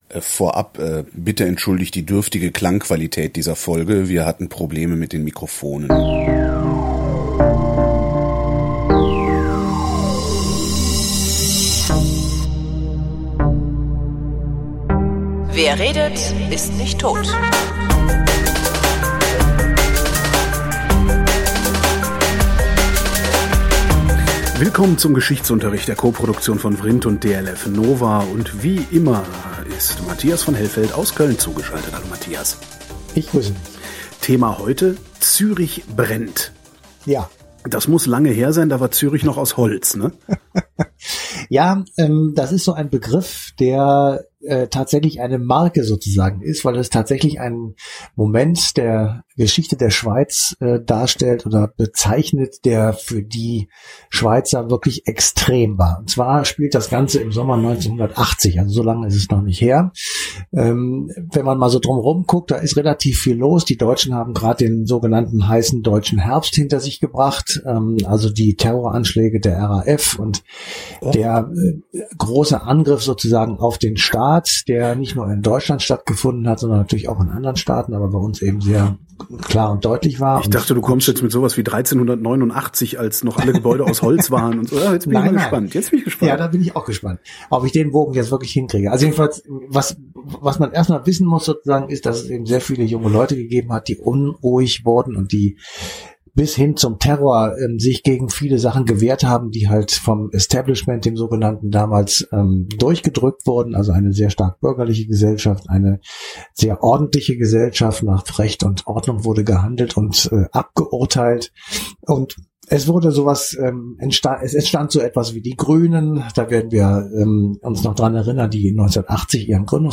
Bitte entschuldigt die schlechte Klangqualität. Es gab Mikrofonprobleme.